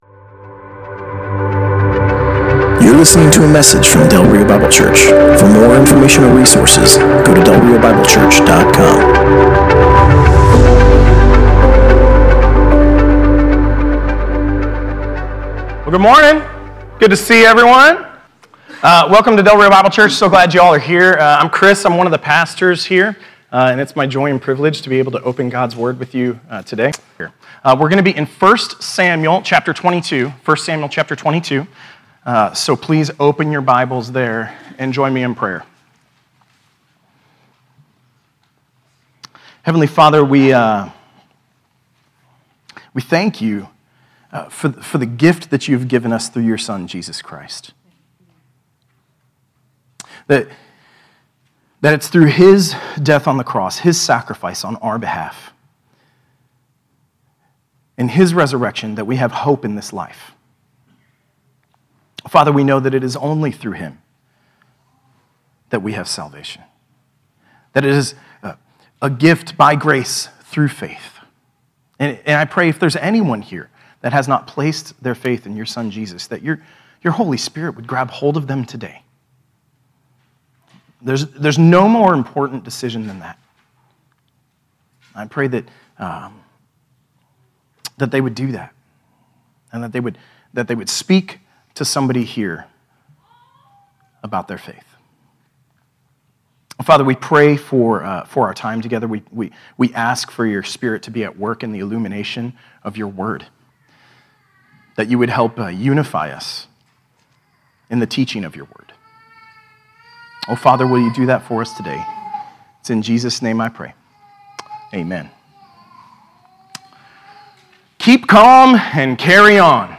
Passage: 1 Samuel 22: 1-23 Service Type: Sunday Morning